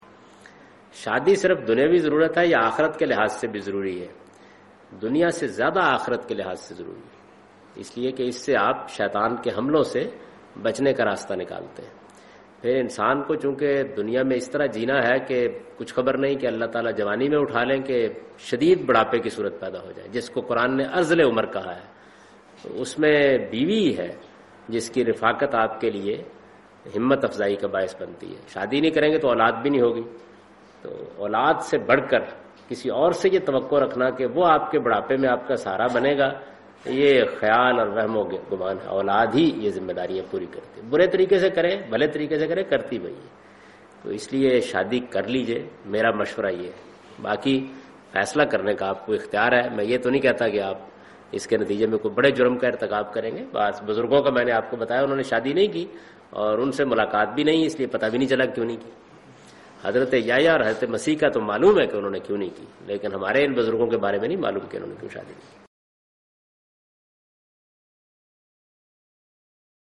Javed Ahmad Ghamidi responds to the question 'Is marraige religious obligation'?
جاوید احمد غامدی اس سوال کا جواب دہے رہے ہیں کہ "کیا شادی ایک دینی فریضہ ہے؟"